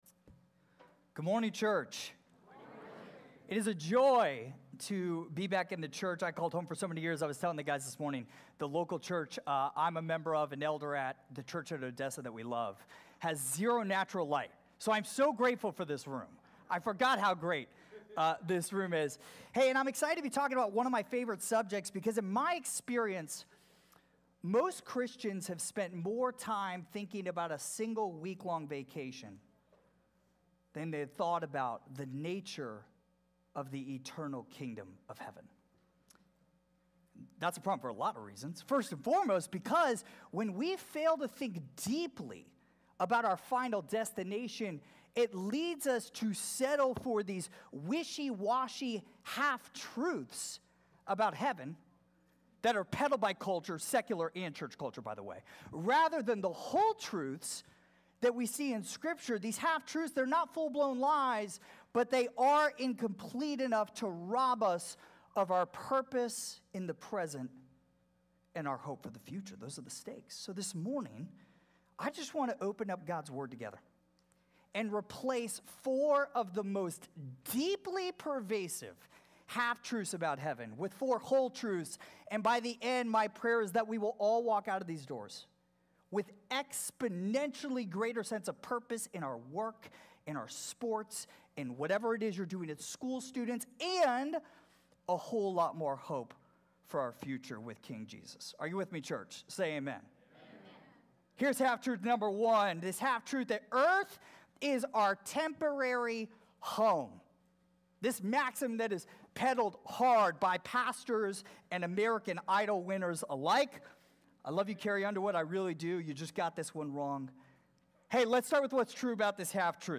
Read the message here: Half Truths About Heaven SERMON